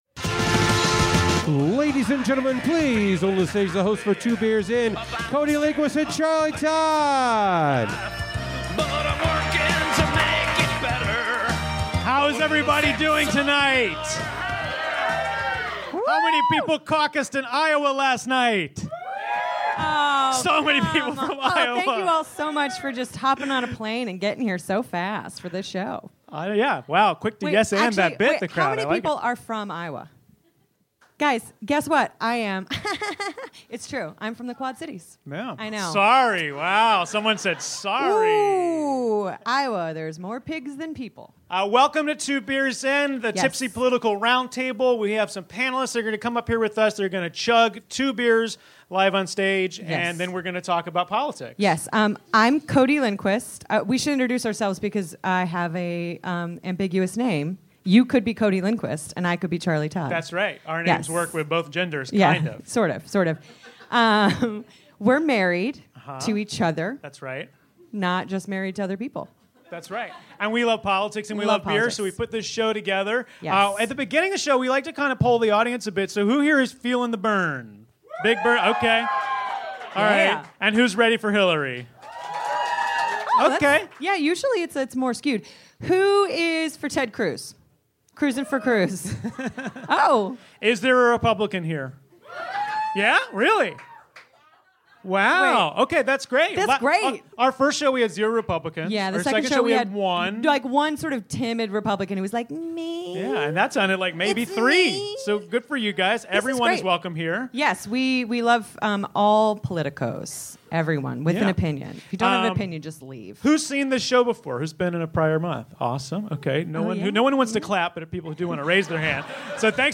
We talk presidential politics in the wake of the Iowa results and get a little too tipsy with Sixpoint Sweet Action. Recorded live from the UCB Theatre East Village on February 2, 2016.